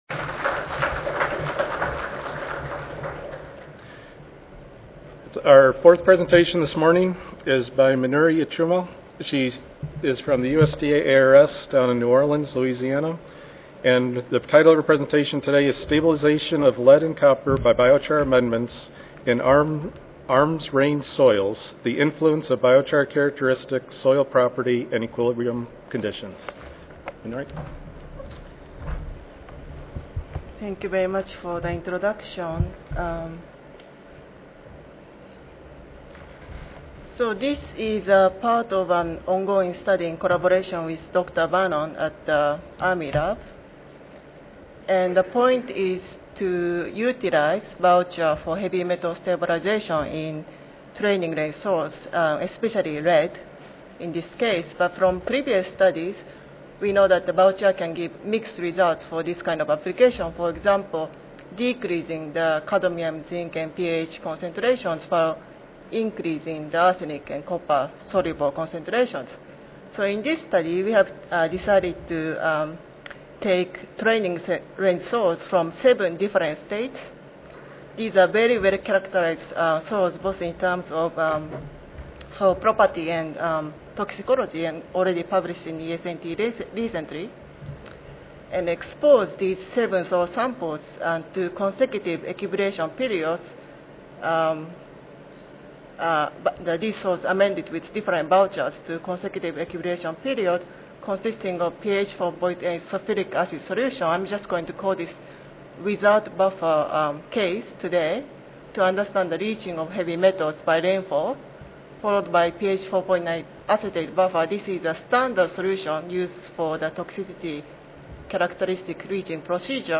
Recorded Presentation Audio File